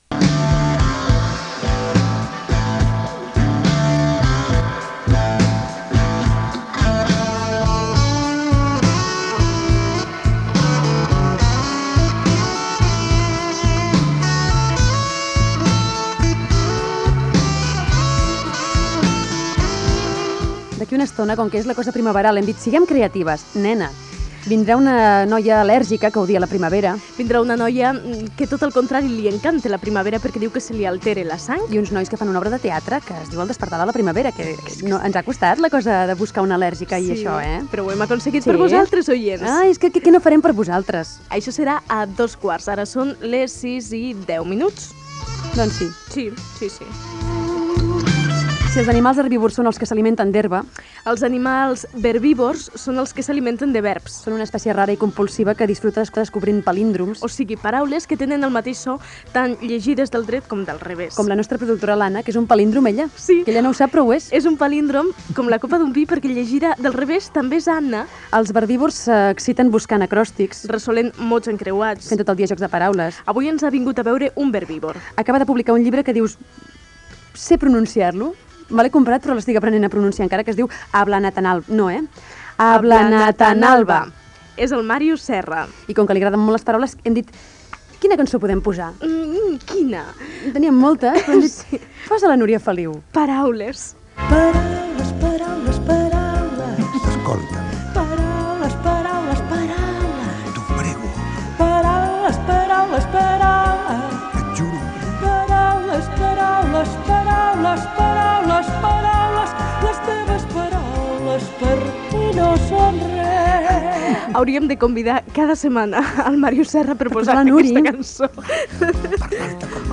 Presentació i entrevista a l'escriptor Màrius Serra que ha publicat el llibre "Ablanatanalba"
Entreteniment